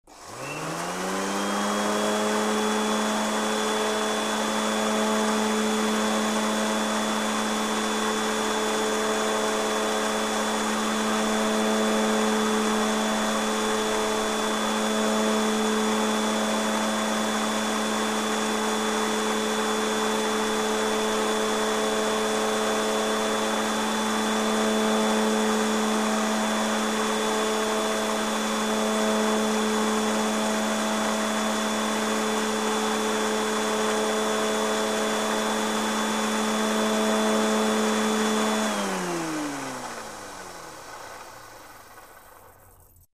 The electric mower turns on, works, turns off: